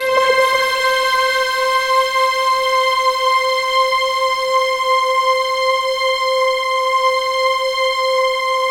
Index of /90_sSampleCDs/USB Soundscan vol.13 - Ethereal Atmosphere [AKAI] 1CD/Partition C/06-POLYSYNTH